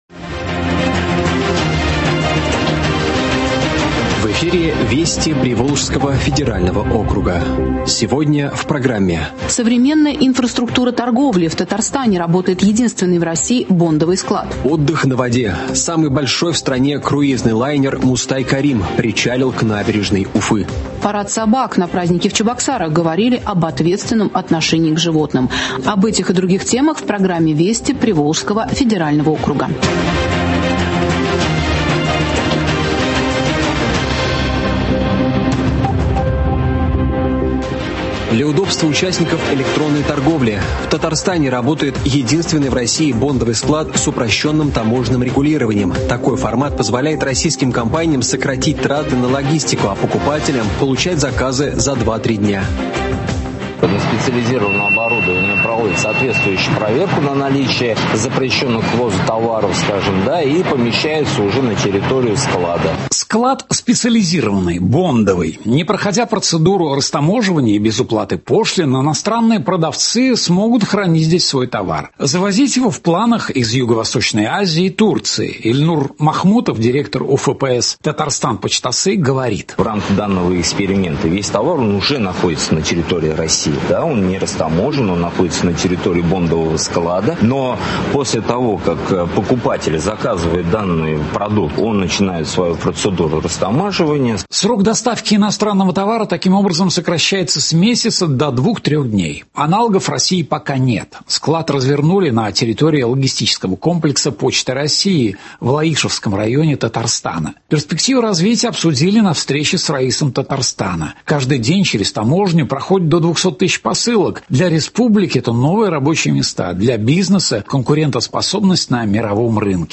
Радиообзор событий в регионах ПФО.